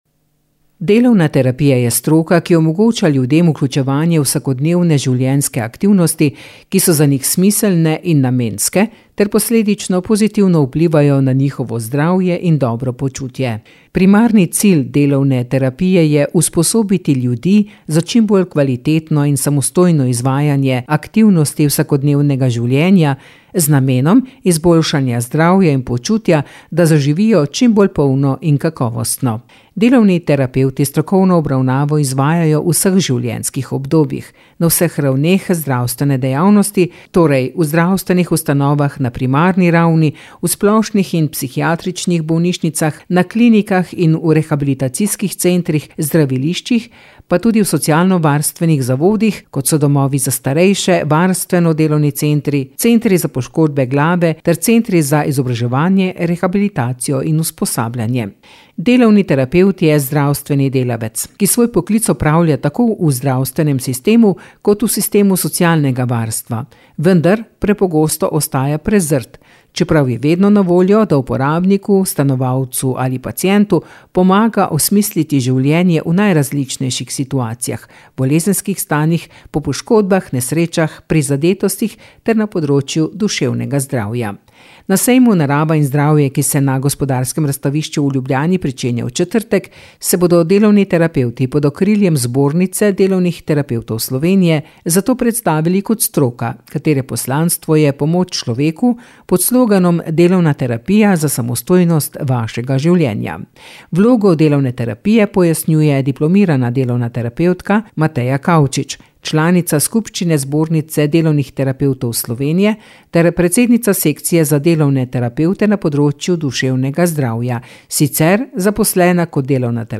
Oddaja Aktualno – Primorski val